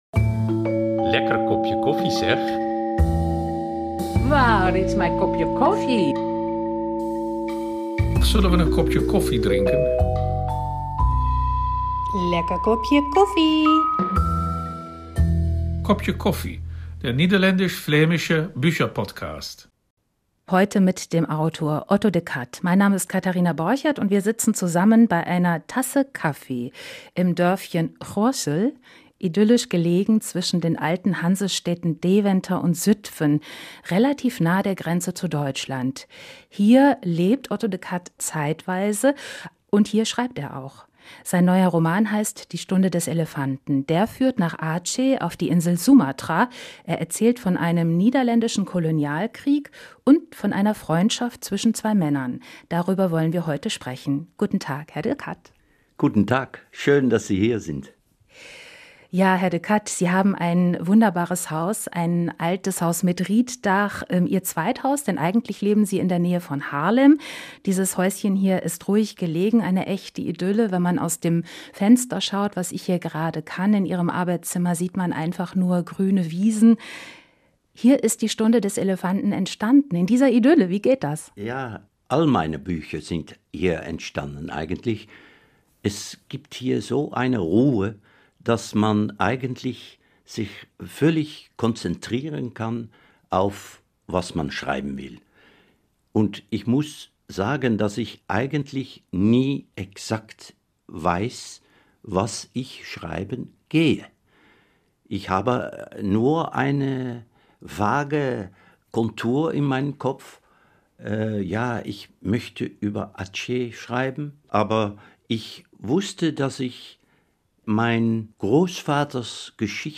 Dabei sind auch Auszüge in Originalsprache und viel Persönliches von ihren Gästen zu hören.